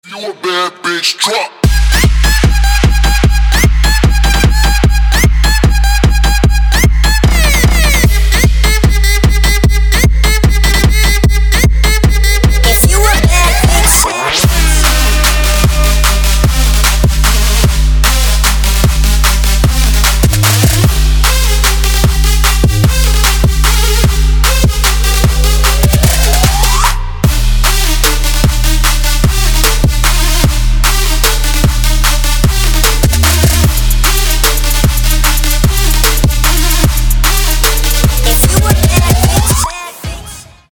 • Качество: 192, Stereo
Trap
трэп